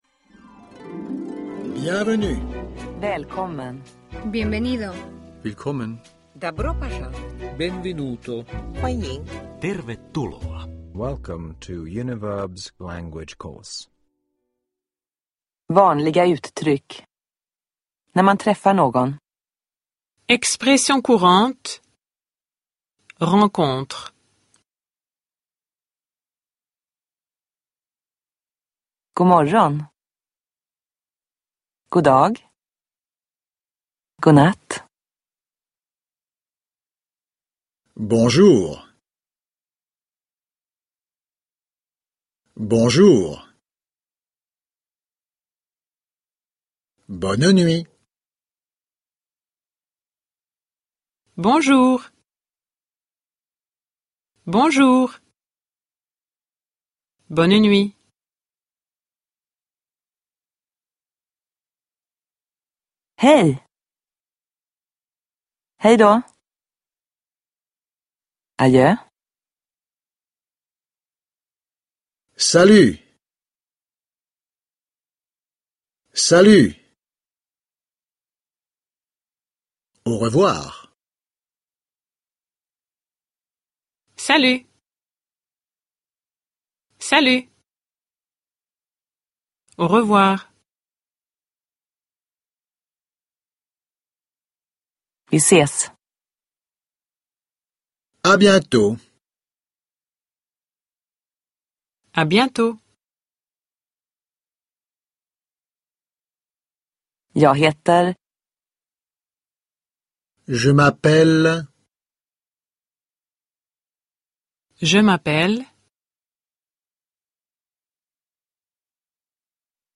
Fransk språkkurs (ljudbok